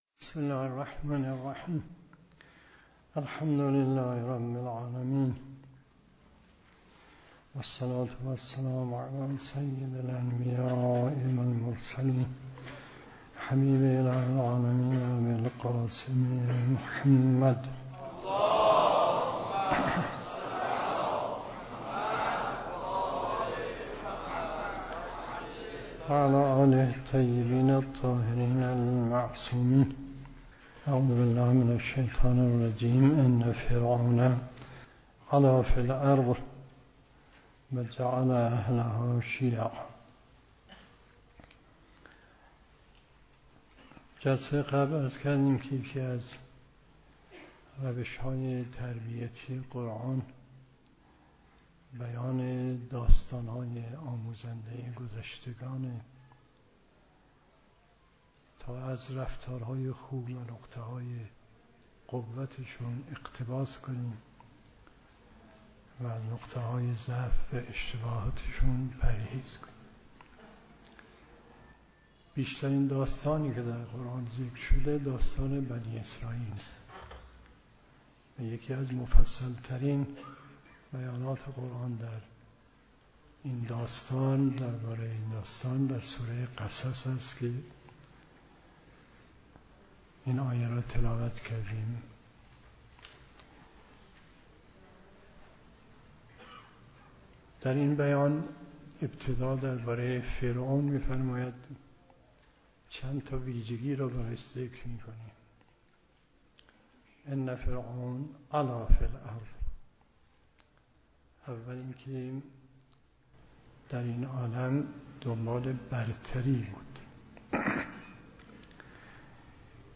محل سخنرانی: دفتر مقام معظم رهبری در قم